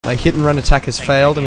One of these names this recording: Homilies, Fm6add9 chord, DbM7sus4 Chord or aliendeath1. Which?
aliendeath1